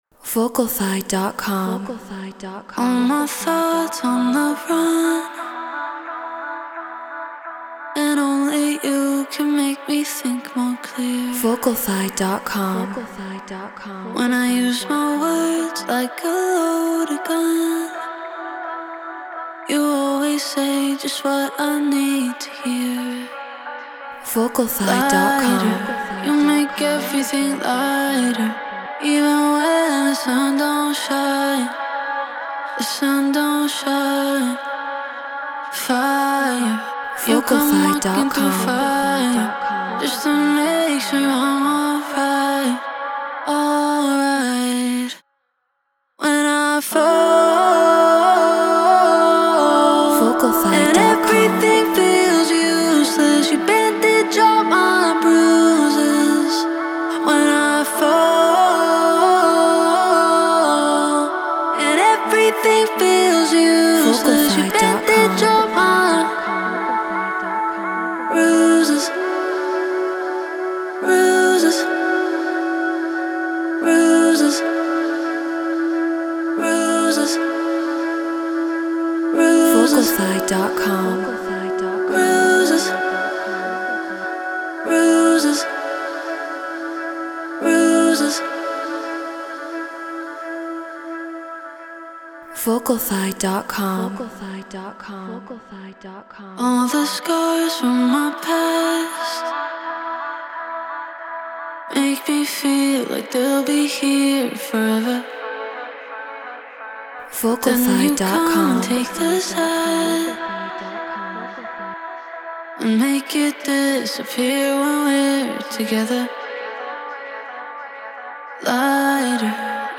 Drum & Bass 174 BPM Bmin
Shure SM7B Apollo Solo Logic Pro Treated Room